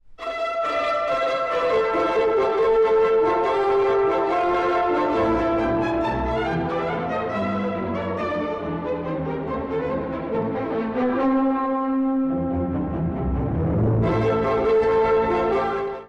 舞い上がるバイオリンの風の中、ホルンが示すのは、《ディエス・イレ》の断片。
音楽全体はとても荒々しく、移り気な形象です。
メインパートは、ロシアのトロイカ…三頭の馬車が荒野を疾走しているよう。